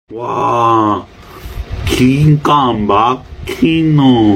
wa giga maghino Meme Sound Effect
This sound is perfect for adding humor, surprise, or dramatic timing to your content.